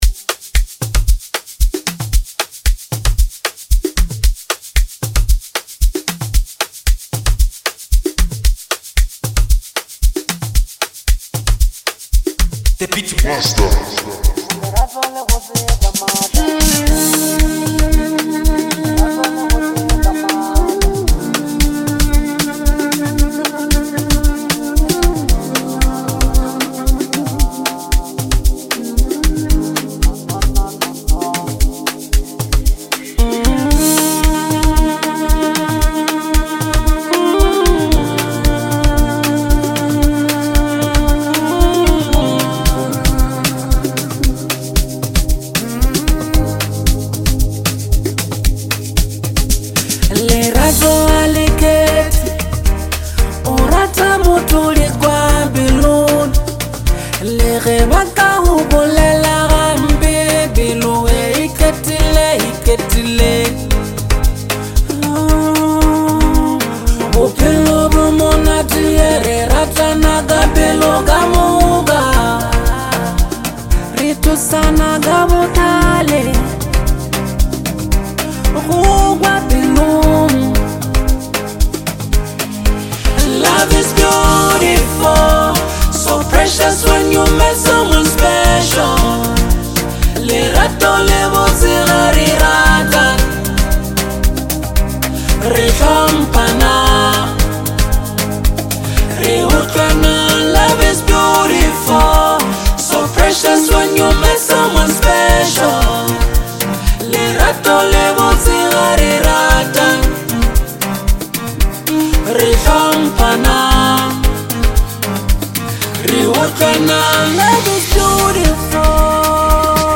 soul stirring track